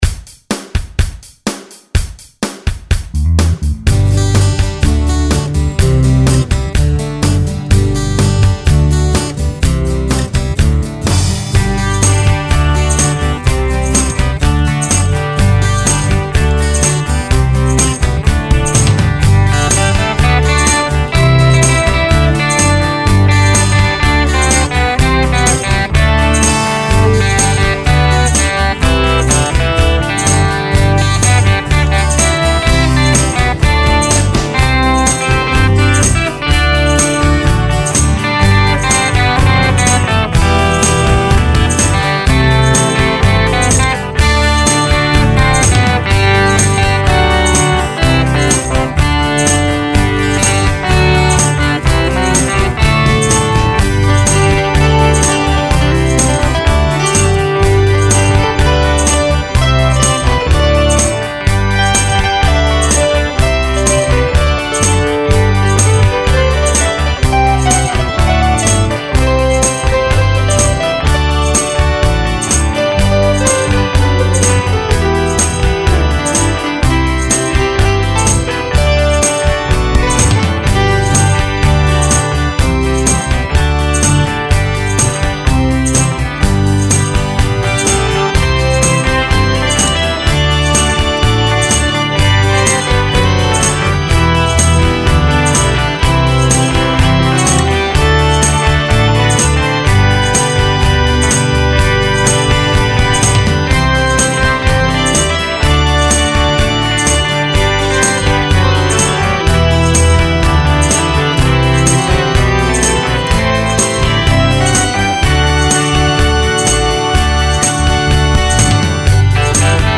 A lot of my songs really amount to nothing more than an excuse to play my Ric 12-string (as if I really need an excuse). This song started when I just began playing a simple little chiming pattern, cycling over and over.